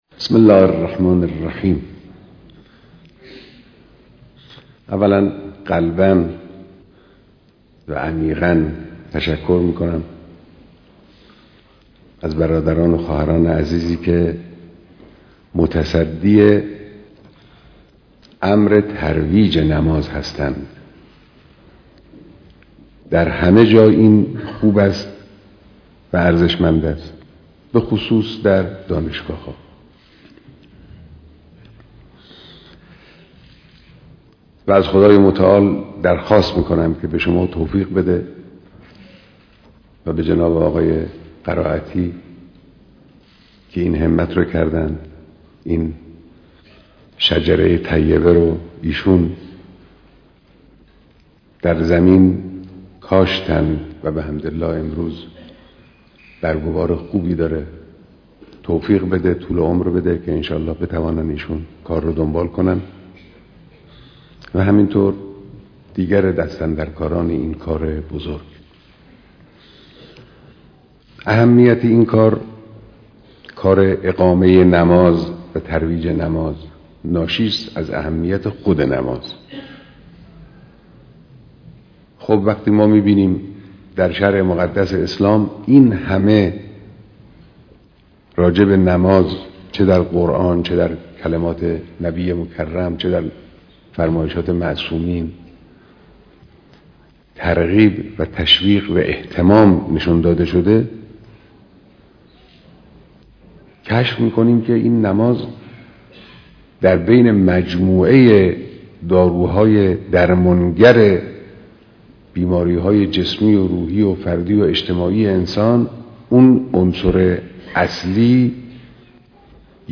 دیدار شرکت کنندگان در هفدهمین اجلاس سراسری نماز